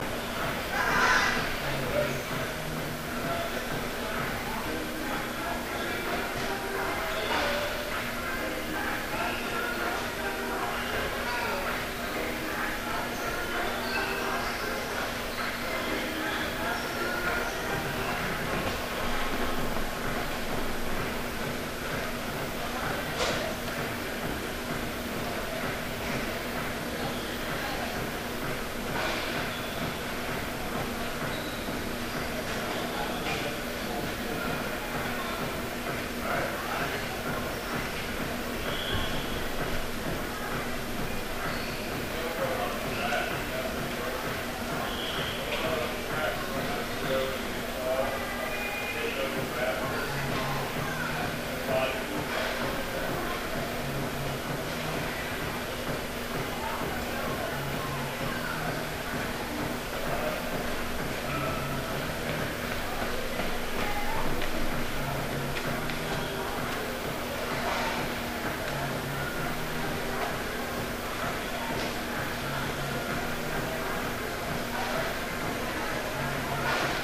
Location: Hofstra Recreational Center, 3/23, 10:30 p.m.
Sounds heard: People laughing/talking, upbeat background music, sneakers squeaking, pounding footsteps from runners on the track
field-recording-8.mp3